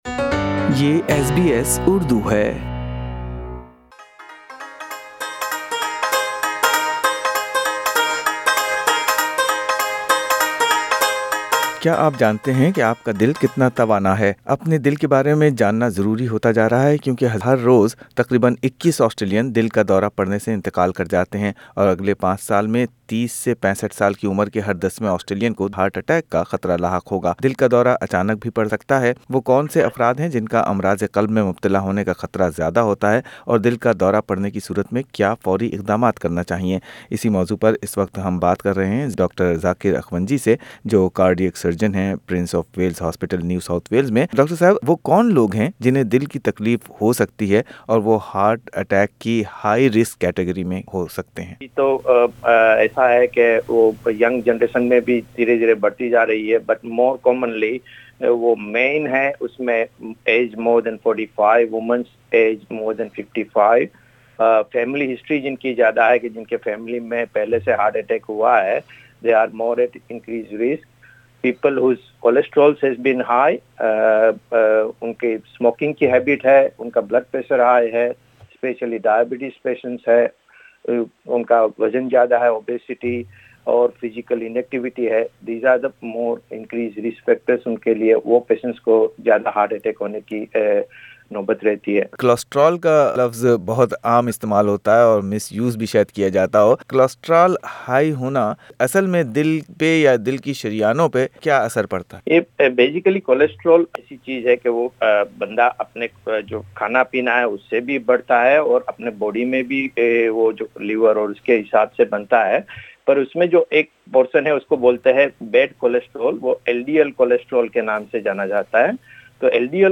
کچھ لوگوں کو بہت دیر میں پتہ چلتا ہے کہ وہ دل کے مریض ہیں کیونکہ انہیں مرض کی کوئی علامات محسوس نہیں ہوتیں مگر کچھ دل کے مرض کی ابتدائی علامات کو نظر انداز کرنے کے باعث بہت دیر کر دیتے ہیں۔ دل کے سرجن اور دل کے مریض اس بارے میں کیا کہتے ہیں، سنئے اس پوڈ کاسٹ میں۔۔